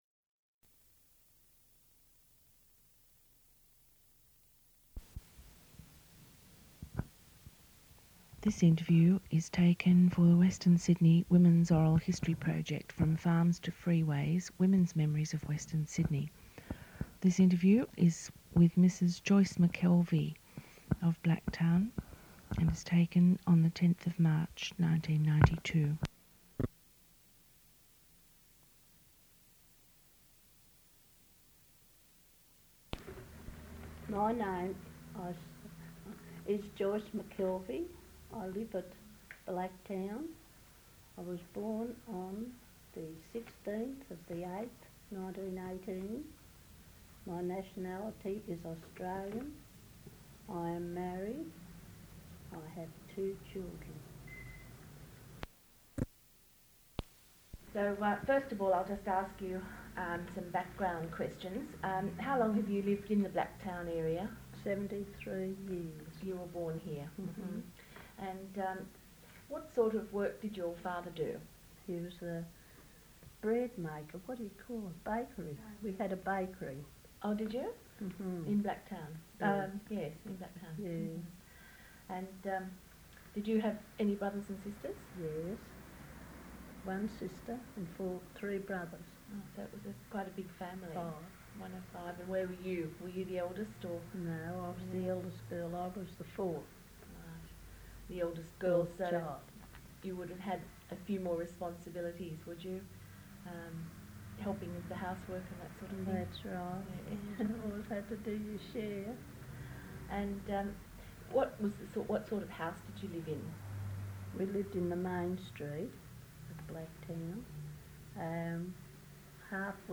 Audio recording of interview
Original Format audio cassette tape (1)